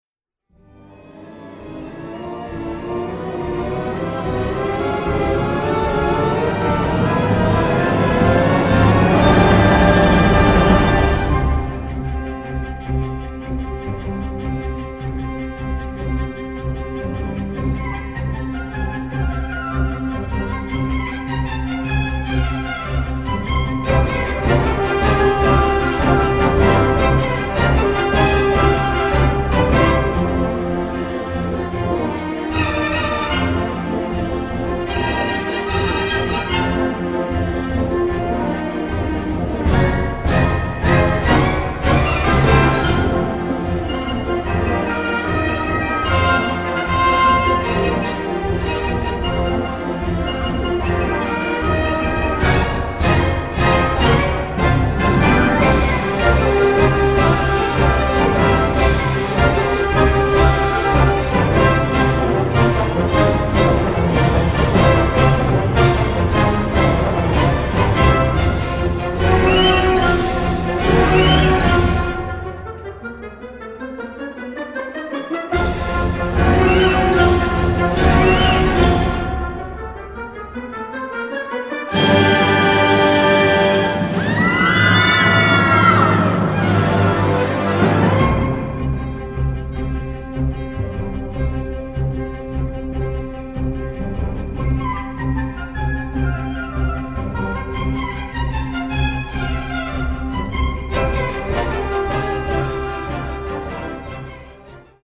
Gattung: A Symphonic Sketch
Besetzung: Sinfonieorchester